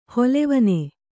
♪ holevane